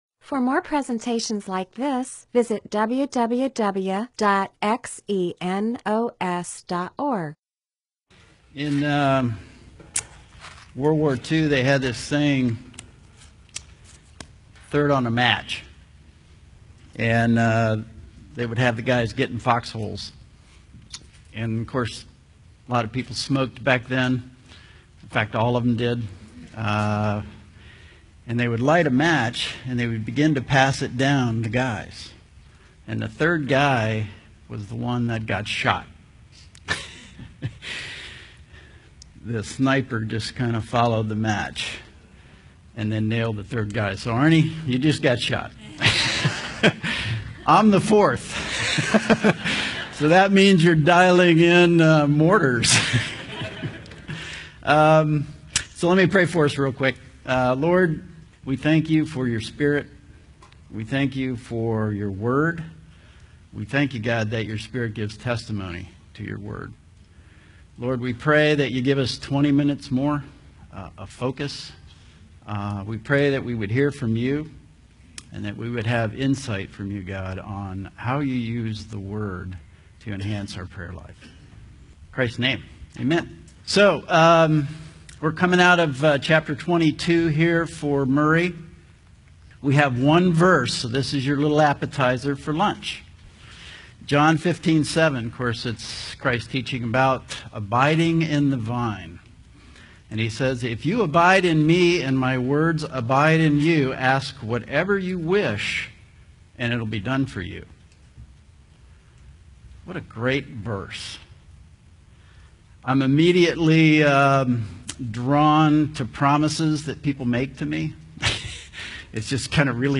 MP4/M4A audio recording of a Bible teaching/sermon/presentation about John 15:7.